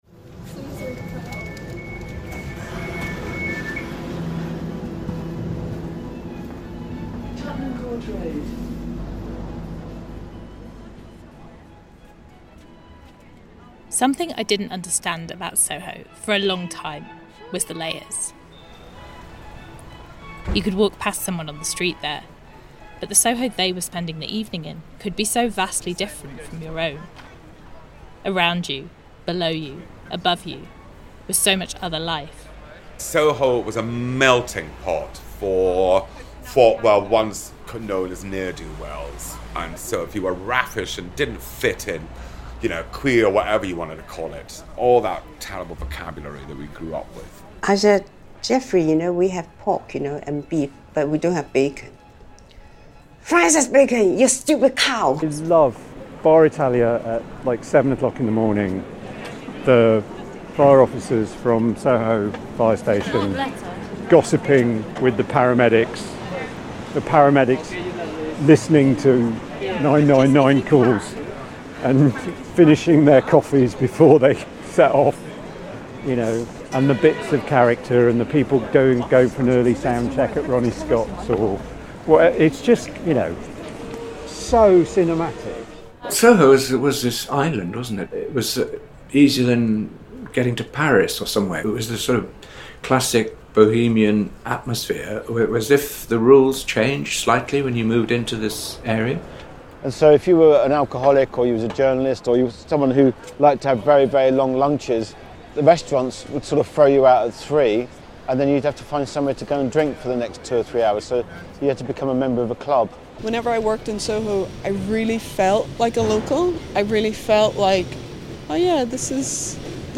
A podcast documenting cooking, eating and domestic life. Often recorded in kitchens.